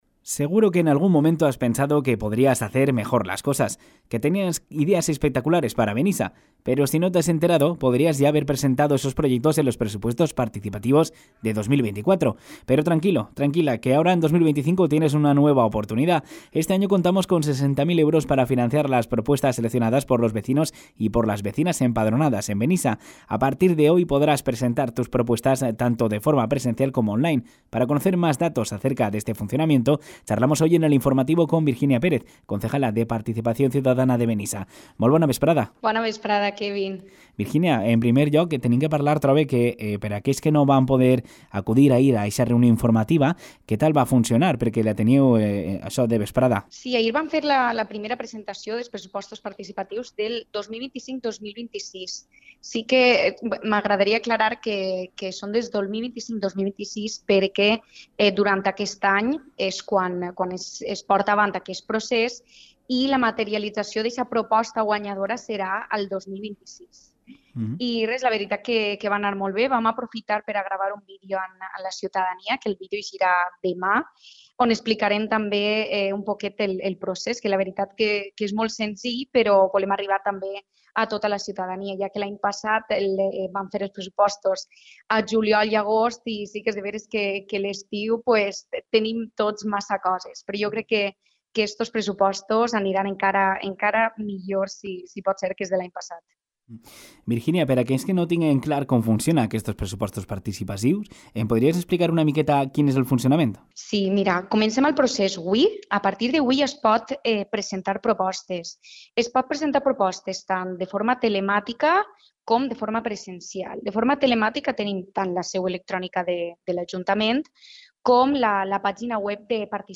Para conocer de cerca cómo funcionan esta iniciativa, hemos podido charlar con la concejala de Participación Ciudadana, Virginia Pérez .
ENTREVISTA-VIRGINIA-PEREZ.mp3